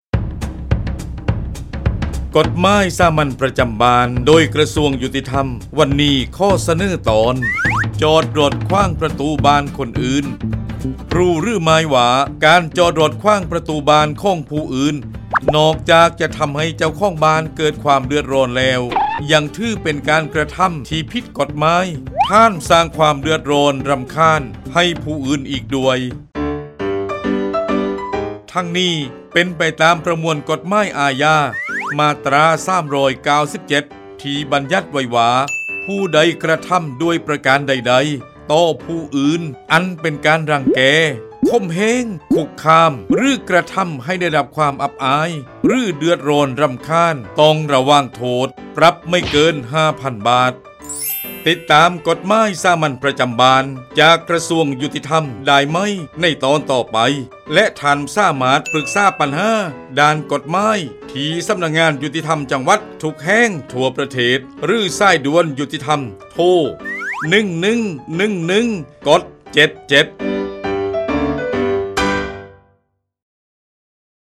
กฎหมายสามัญประจำบ้าน ฉบับภาษาท้องถิ่น ภาคใต้ ตอนจอดรถขวางประตูบ้านคนอื่น
ลักษณะของสื่อ :   บรรยาย, คลิปเสียง